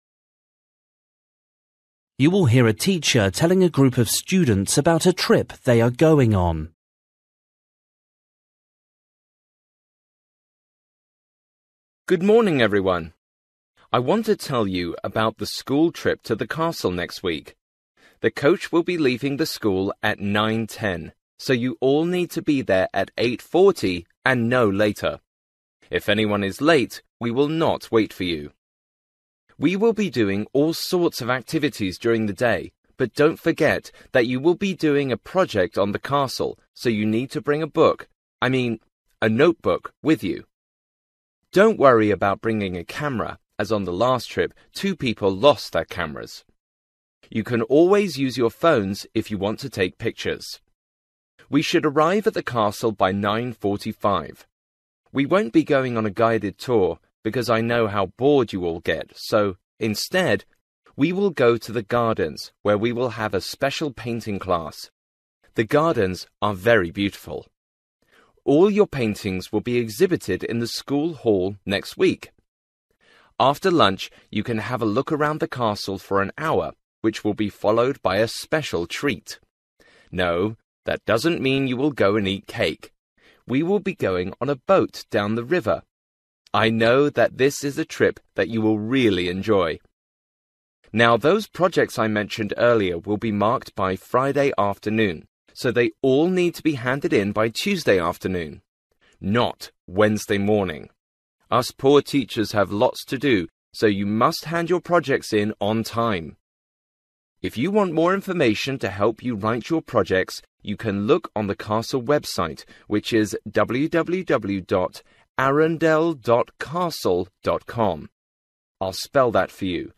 You will hear a teacher telling a group of students about a trip they are going on.